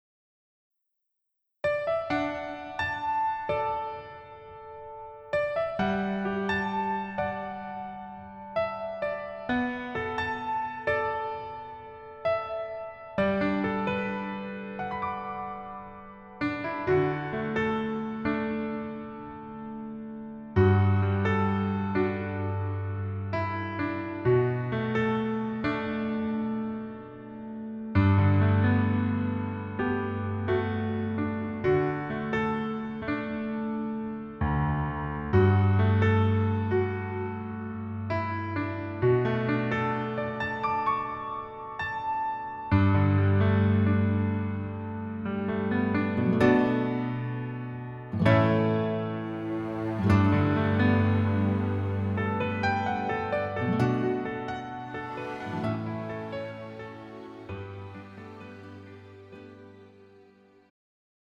음정 -1키 3:34
장르 가요 구분 Pro MR